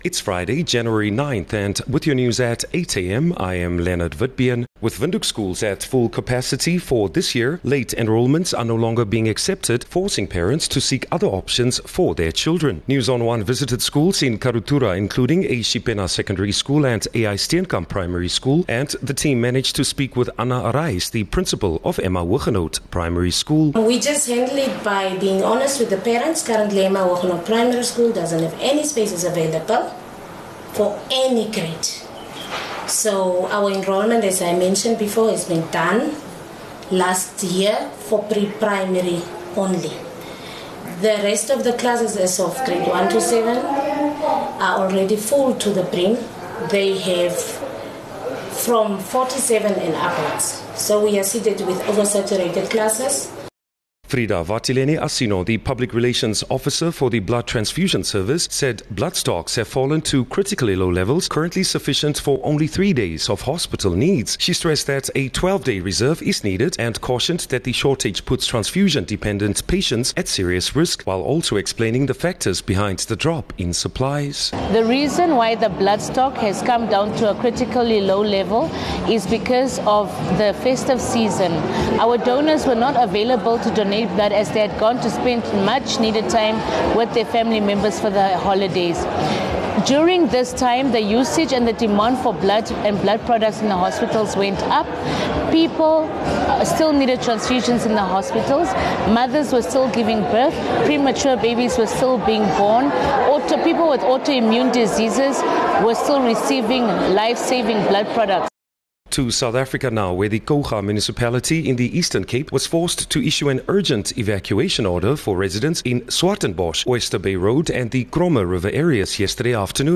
Daily bulletins from Namibia's award winning news team.